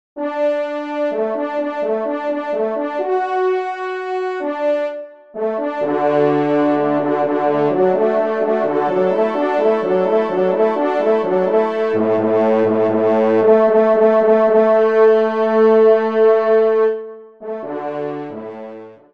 Genre : Musique Religieuse pour Trois Trompes ou Cors
Pupitre 3° Trompe